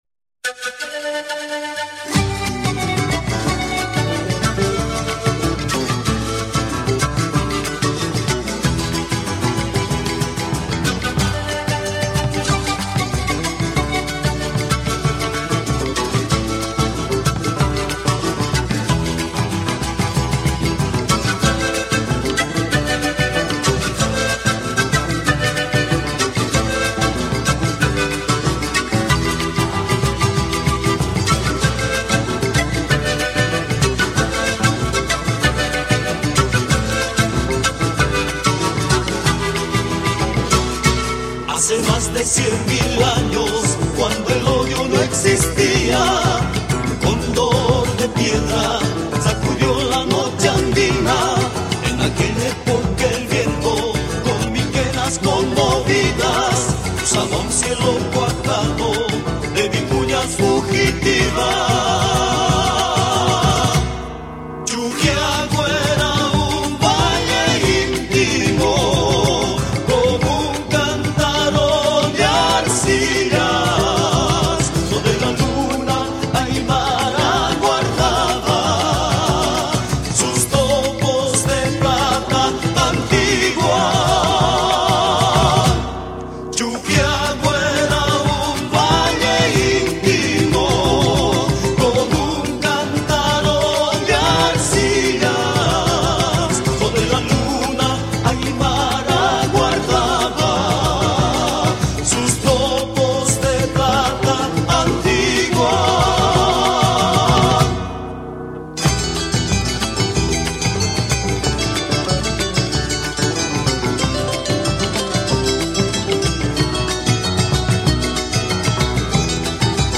Trough the sounds of panpipes